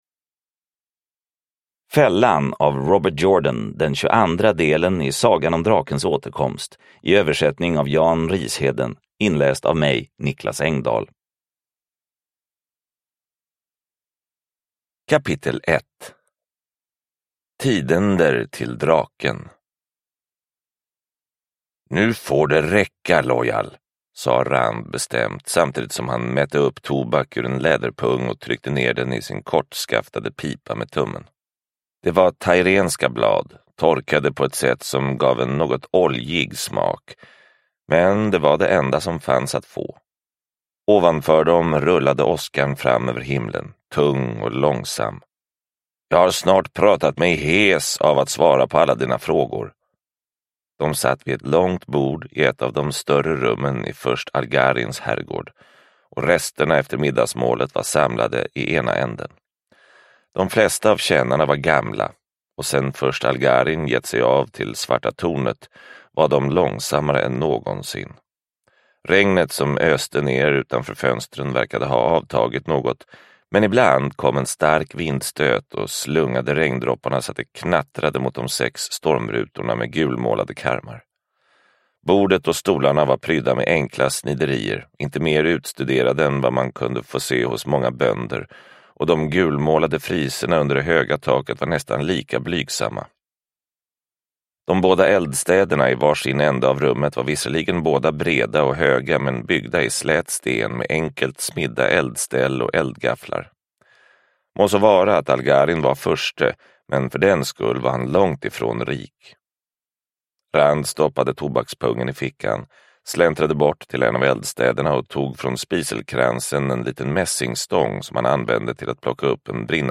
Fällan – Ljudbok – Laddas ner